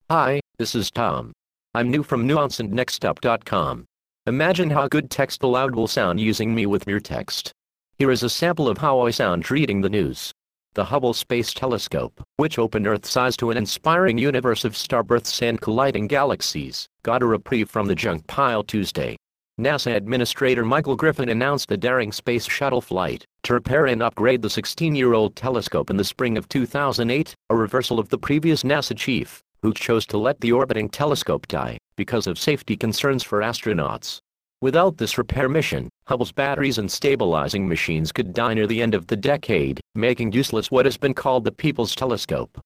Texte de d�monstration lu par Tom (Nuance RealSpeak; distribu� sur le site de Nextup Technology; homme; anglais)